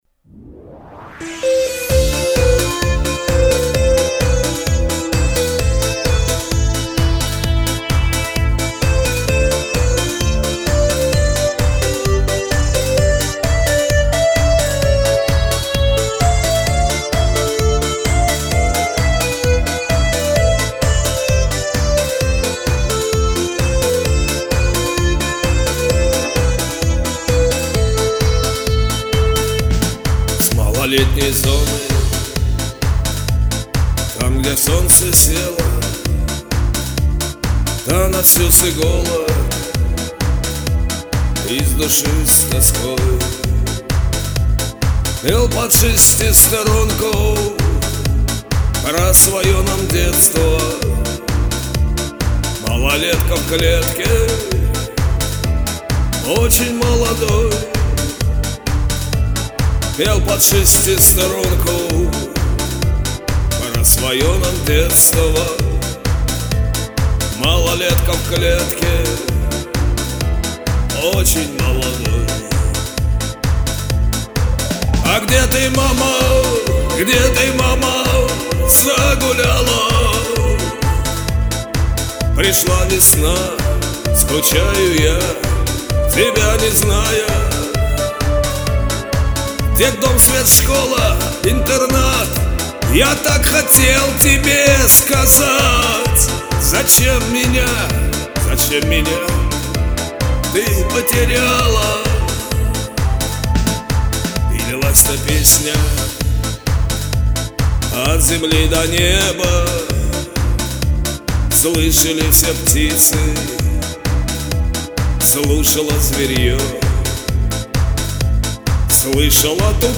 Ведь о маме поёте,а не чувствуется.